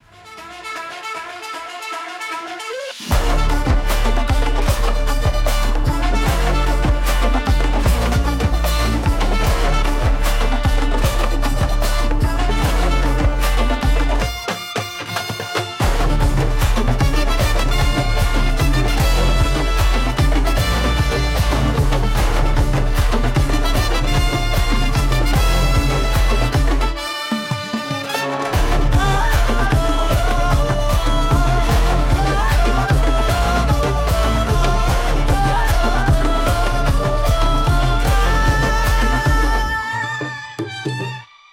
mass entry BGM